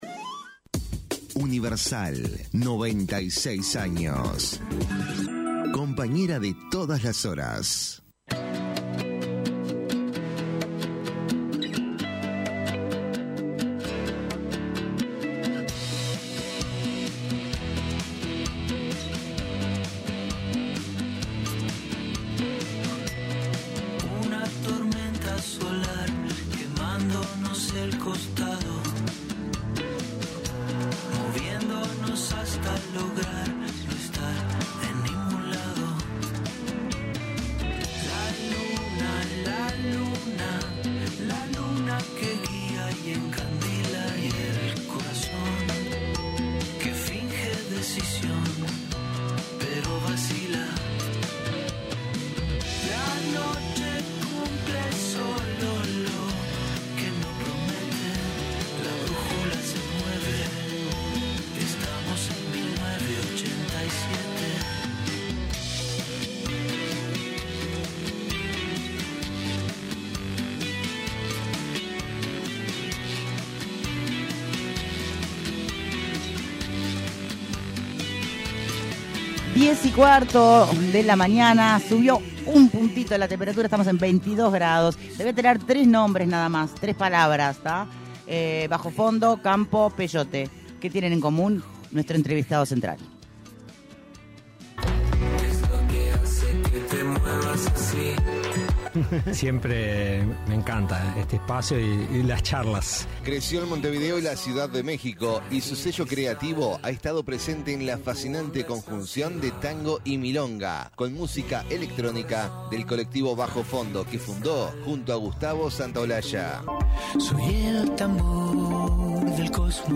El músico y productor Juan Campodónico, en entrevista con Punto de Encuentro, habló de cómo fue empezar a trabajar con El Cuarteto de Nos, por qué se dio cuenta que iba a pegar en América Latina y su idea de hacer «música perdurable».